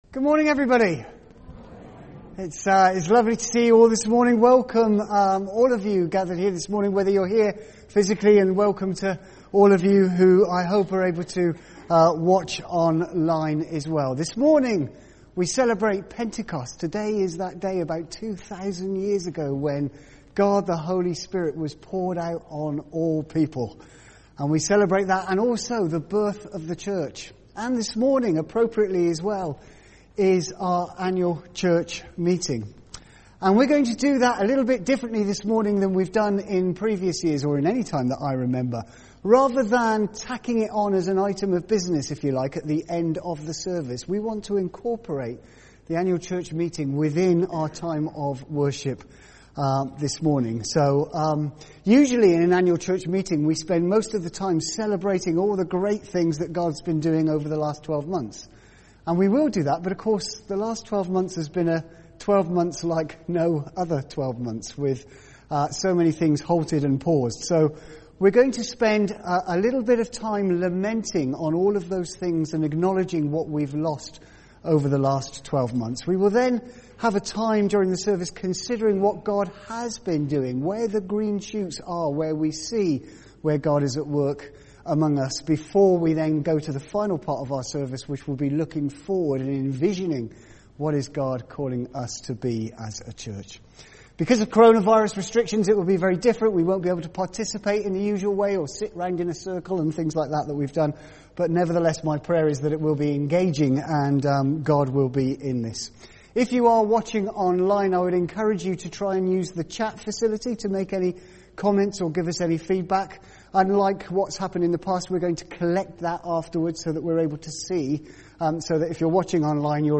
A message from the service
From Service: "10.30am Service"